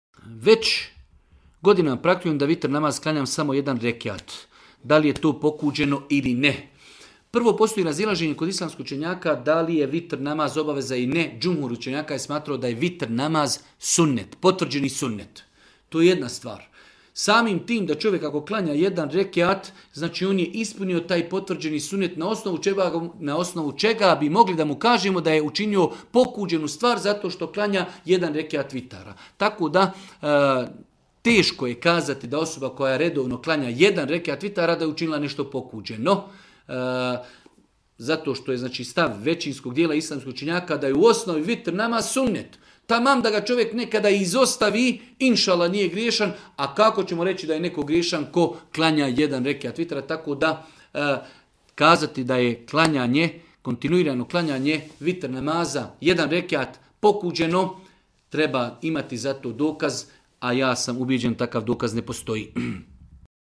u video predavanju ispod.